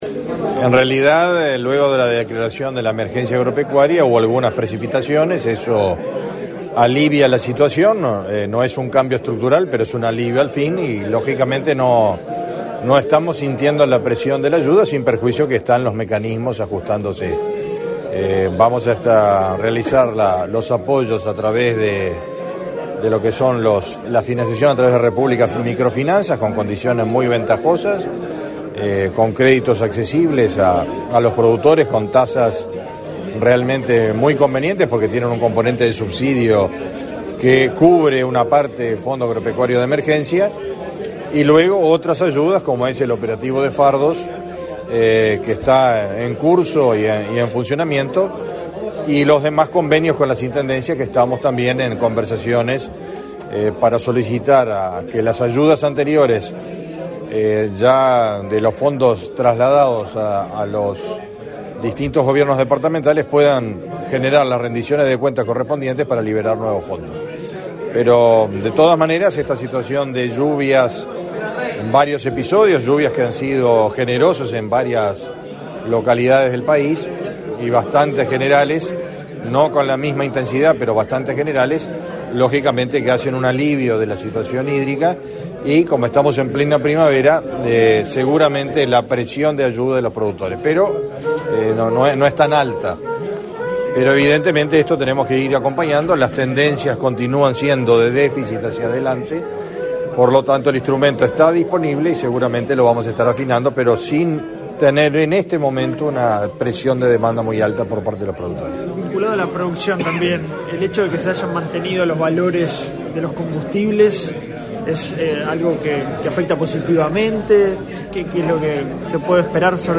Declaraciones a la prensa del ministro del MGAP, Fernando Mattos
Declaraciones a la prensa del ministro del MGAP, Fernando Mattos 01/11/2022 Compartir Facebook Twitter Copiar enlace WhatsApp LinkedIn La primera edición de Mercoláctea en Uruguay será los días 1, 2 y 3 de junio de 2023, en el predio de la Asociación Rural del Uruguay. Este 1 de noviembre se realizó el acto de lanzamiento. Antes del evento, el titular del Ministerio de Ganadería, Agricultura y Pesca (MGAP) realizó declaraciones a la prensa.